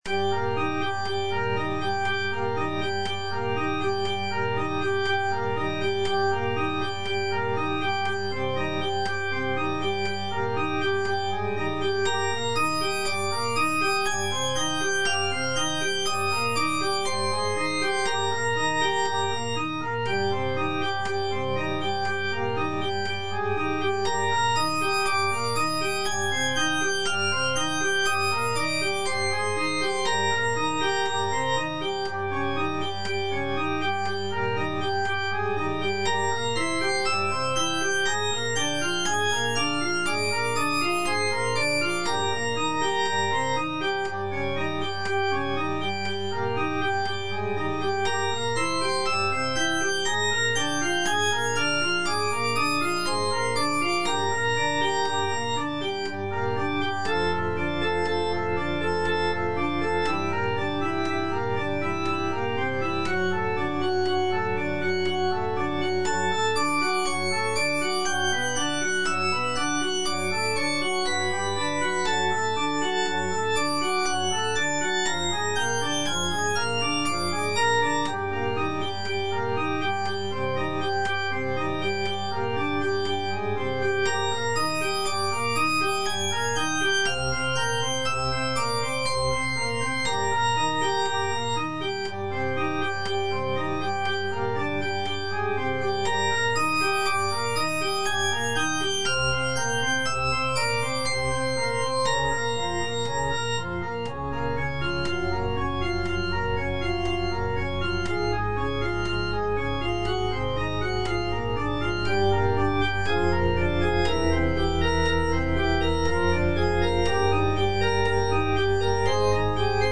G. FAURÉ - REQUIEM OP.48 (VERSION WITH A SMALLER ORCHESTRA) Sanctus - Alto (Voice with metronome) Ads stop: